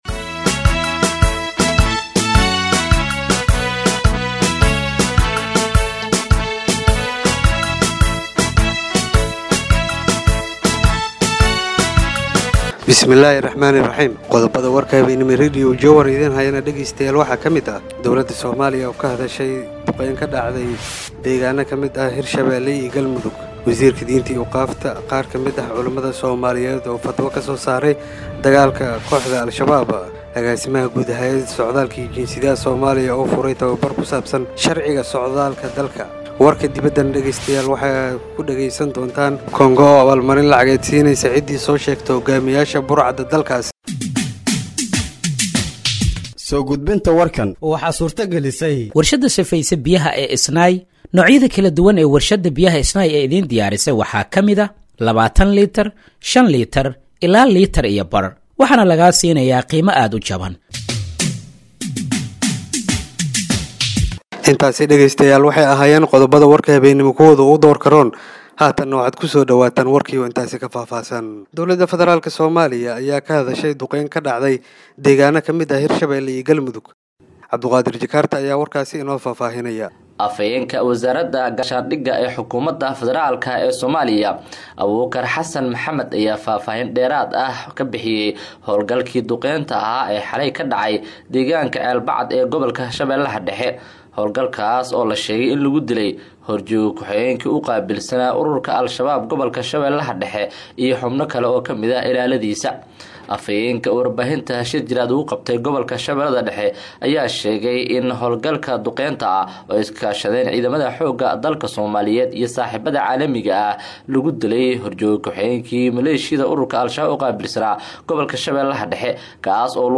Dhageeyso Warka Habeenimo ee Radiojowhar 09/03/2025
Halkaan Hoose ka Dhageeyso Warka Habeenimo ee Radiojowhar